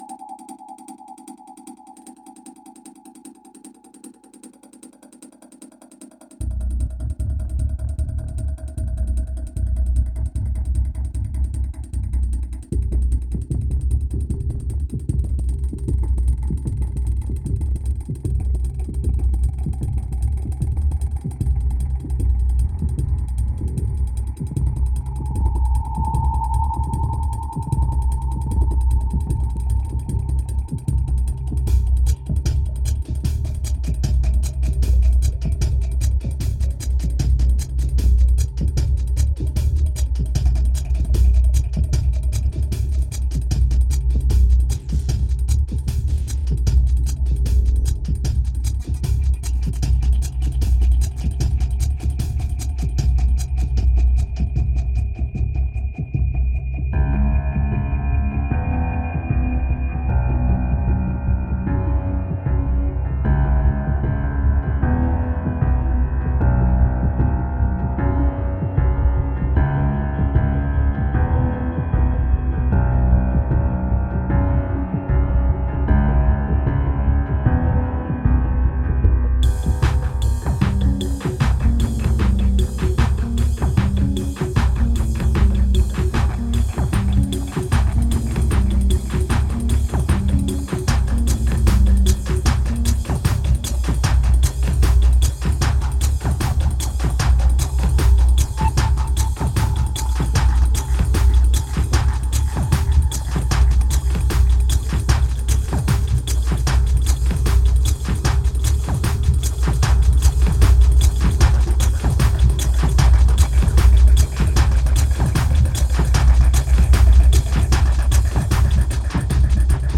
2485📈 - 6%🤔 - 76BPM🔊 - 2011-08-08📅 - -115🌟